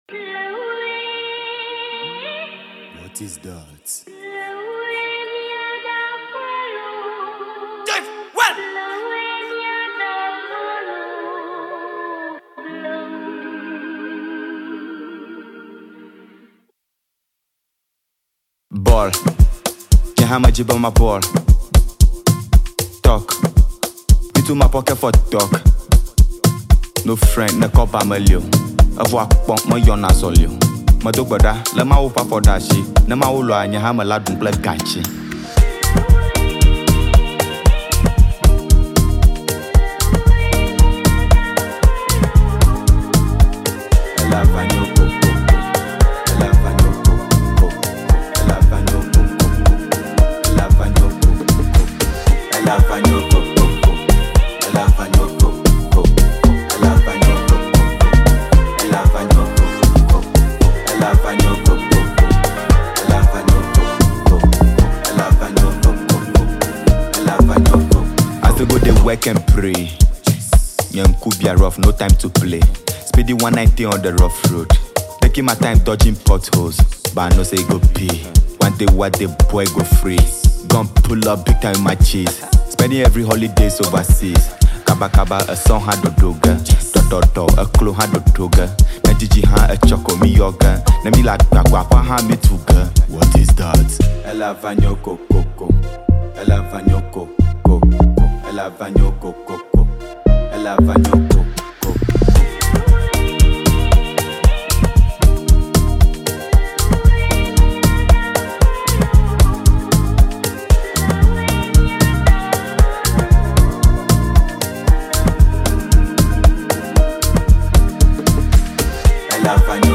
whether you’re a fan of Amapiano, Afrobeats